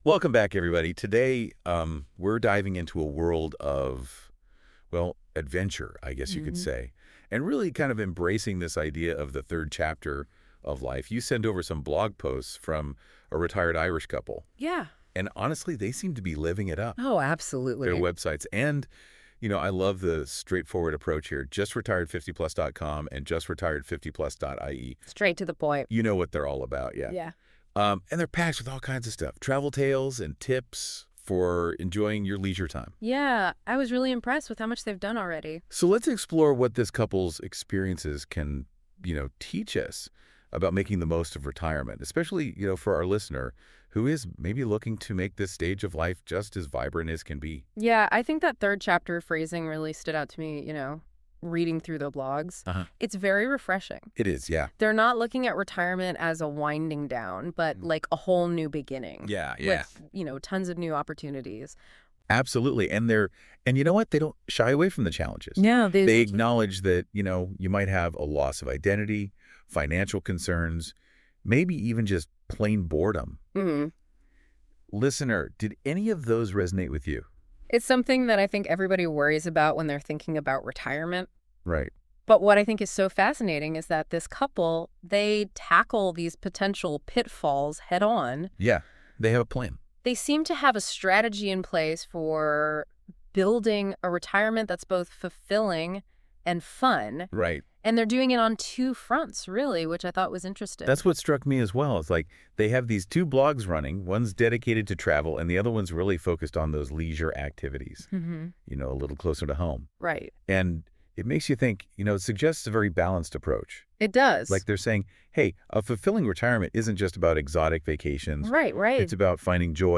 PodCast of this blog in conversation form available for easy listening ( Link from icon above ) Facing a blank schedule, and finding things to fill in those blanks, can be the toughest part of retirement.